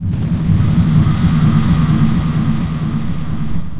breathe2.ogg